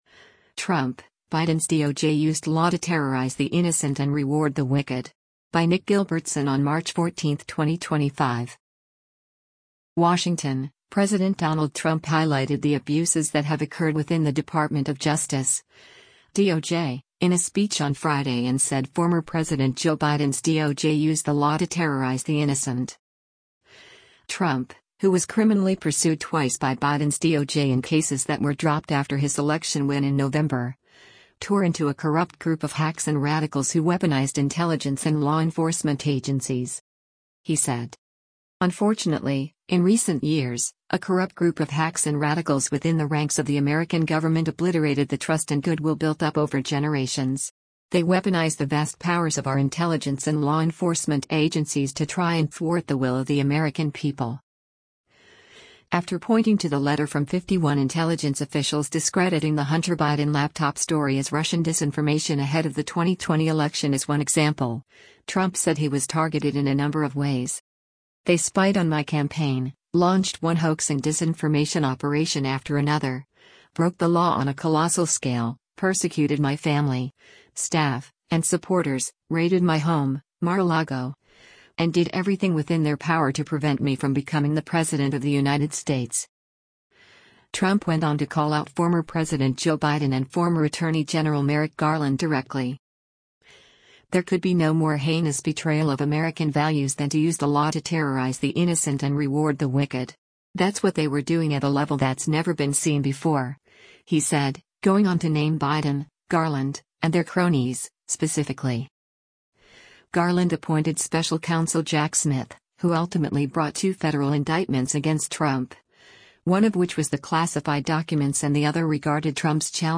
WASHINGTON—President Donald Trump highlighted “the abuses that have occurred within” the Department of Justice (DOJ) in a speech on Friday and said former President Joe Biden’s DOJ used “the law to terrorize the innocent.”